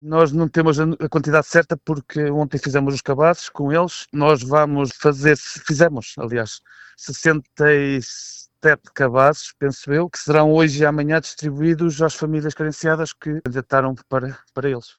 O presidente da Junta de Freguesia, David Vaz, sublinha que a iniciativa superou largamente as expectativas iniciais: